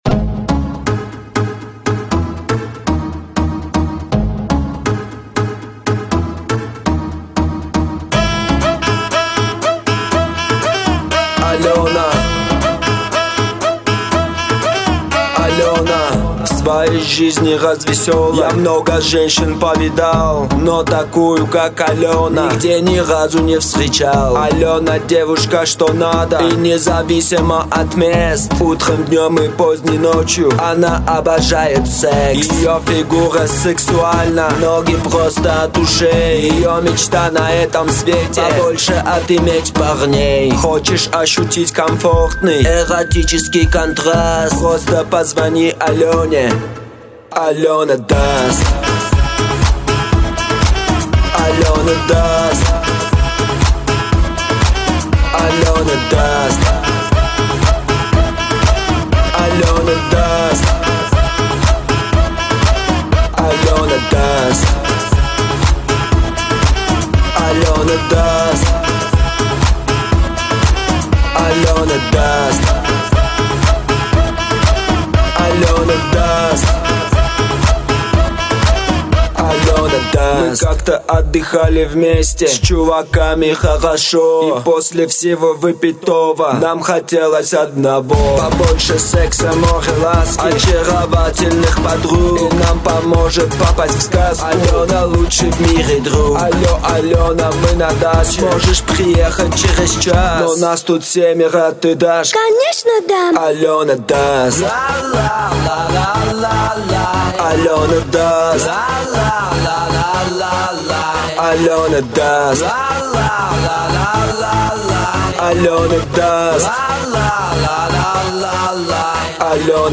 Категория: Pop, Euro-pop